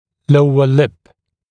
[‘ləuə lɪp][‘лоуэ лип]нижняя губа